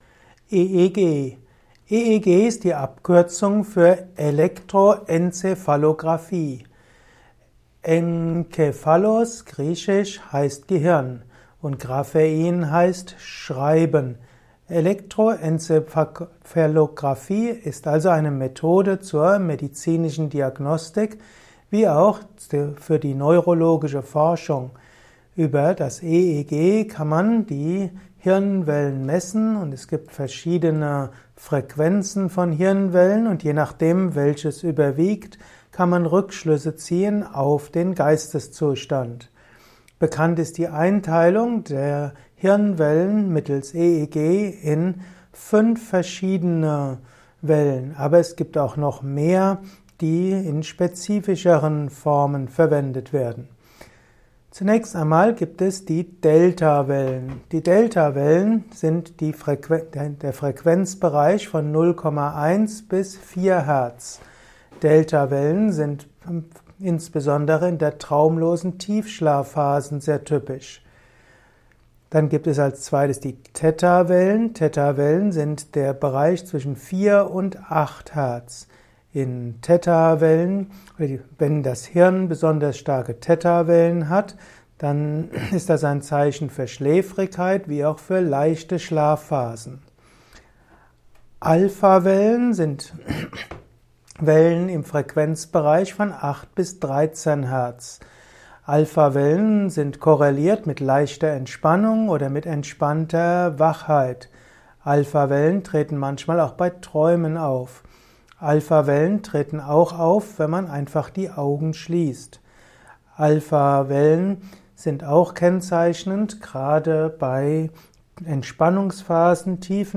Vortragsaudio rund um das Thema EEG. Erfahre einiges zum Thema EEG in diesem kurzen Improvisations-Vortrag.